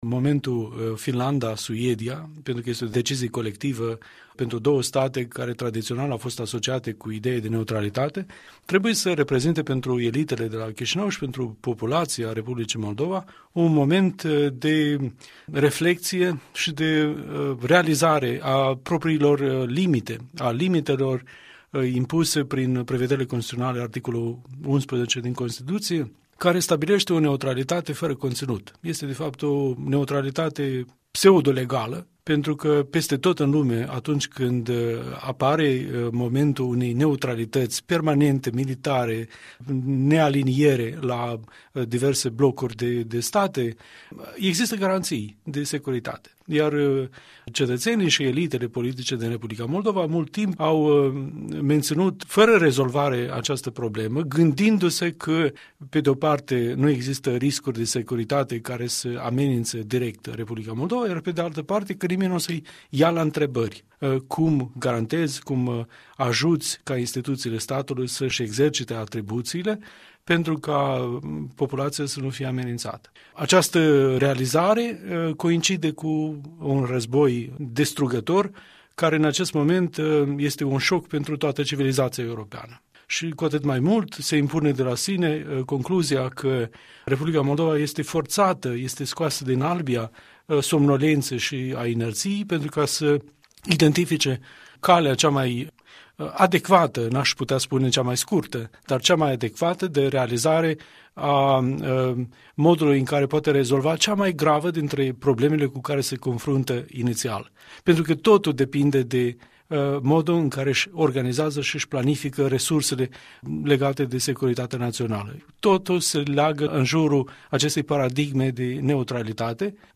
Interviu cu Igor Munteanu despre limitele impuse R. Moldova de „neutralitate”